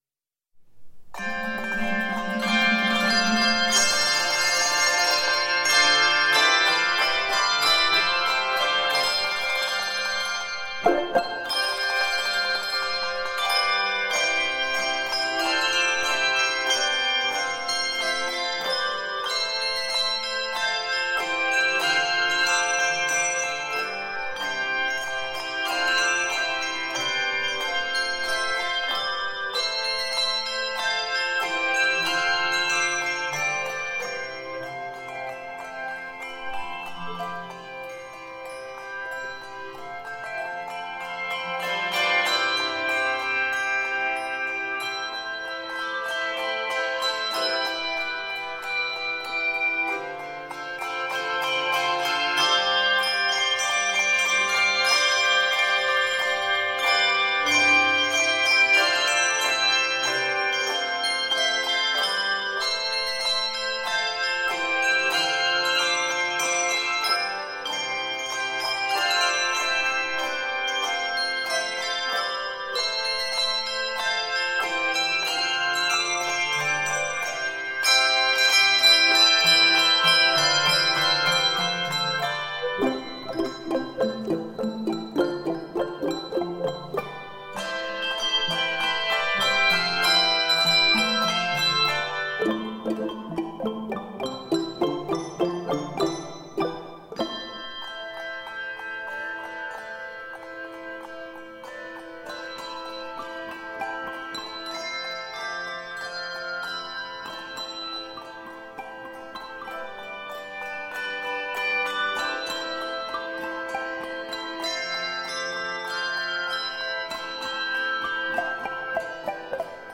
It is scored in C Major and is 88 measures.